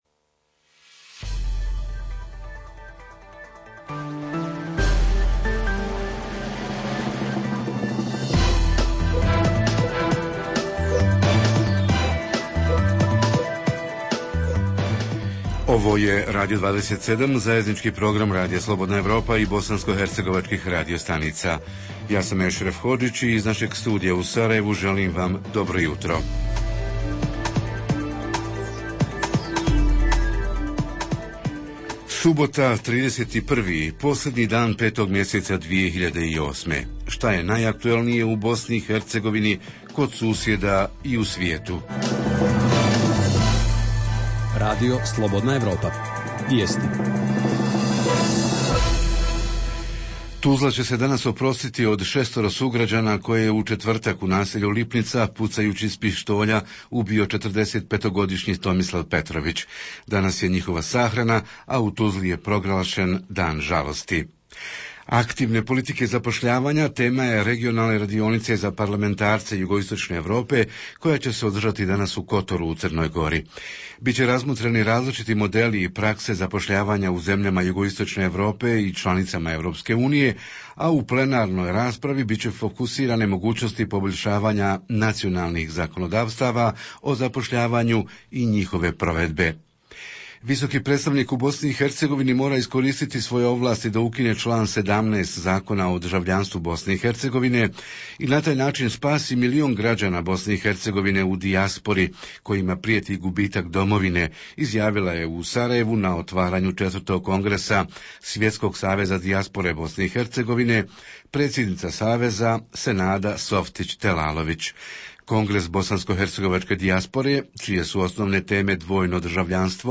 Tema ovog jutra: saobraćaj, putevi i “crne tačke” – na kojim je lokacijama najviše saobraćajnih udesa – da li su ta mjesta adekvatno označena, šta nadležni (direkcije za ceste i lokalna vlast) poduzimaju kako bi na tim lokacijama obezbijedili uvjete za sigurniji saobraćaj: adekvatna signalizacija, rekonstrukcija “crnih tački” (oštrih krivina, pogrešnog nagiba, suženog kolovoza, saobraćajnice pored škola i sl.)? Redovna rubrika Radija 27 subotom je “Estrada i show bussines”. Redovni sadržaji jutarnjeg programa za BiH su i vijesti i muzika.